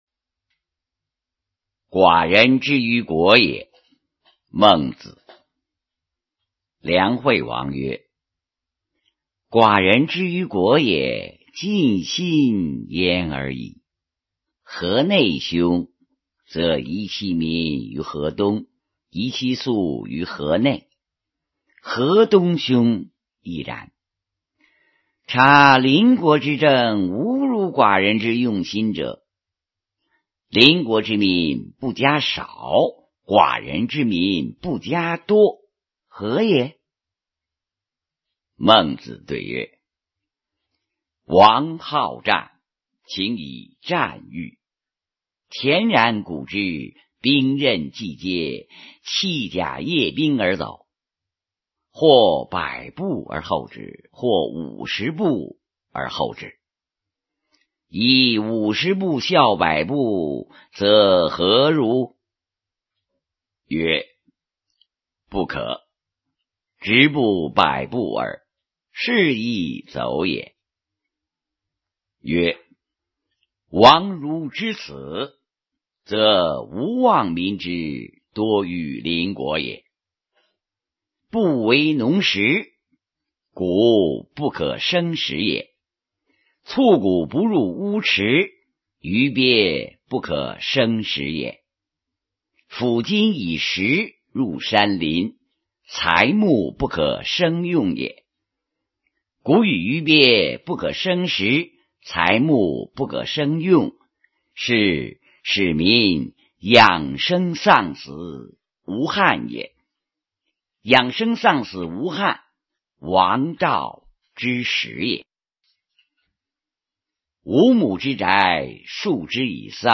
首页 视听 语文教材文言诗文翻译与朗诵 高中语文必修三